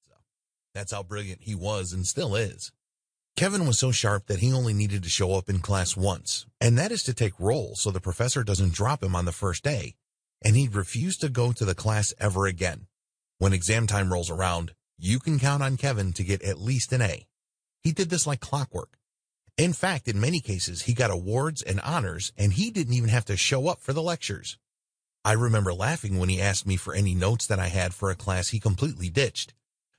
ThePowerofSelf-DisciplineAudiobookfull.mp3